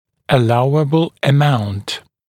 [ə’lauəbl ə’maunt][э’лауэбл э’маунт]допустимая величина